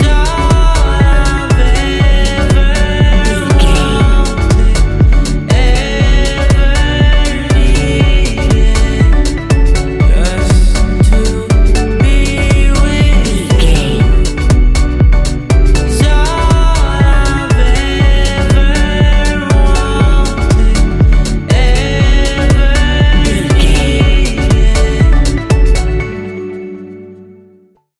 Ionian/Major
E♭
house
electro dance
synths
techno
trance